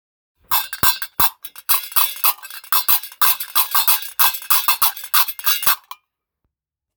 鉄カスタネット特大 25CM
モロッコのグナワ音楽に欠かせない鉄カスタネット別名「カルカバ・カルカベ」Qarqabaの大判タイプです。通常左右の手に一個ずつもち、2個で複雑なリズムを奏でます。
素材： 鉄 ビニール